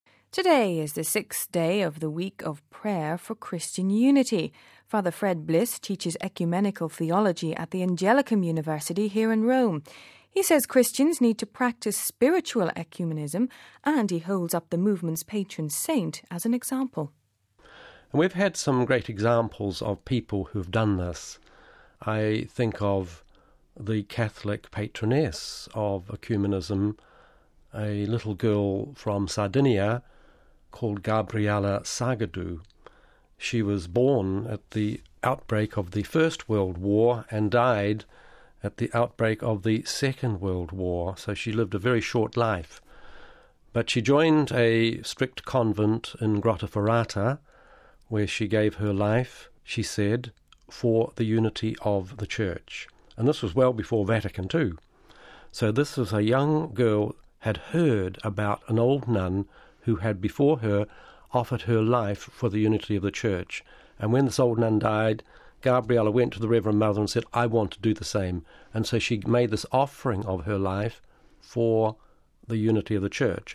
Home Archivio 2007-01-23 08:57:57 The Patron Saint of Christian Unity (23 Jan 07 - RV) It is not surprising that Ecumenism has a patron saint, but who would expect it to be an Italian who never met a non-Catholic? We have this report...